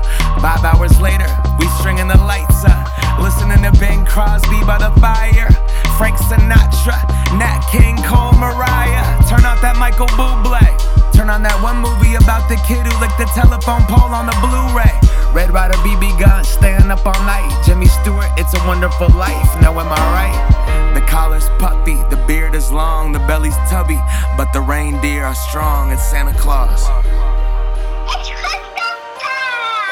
Genre: Hip-Hop/Rap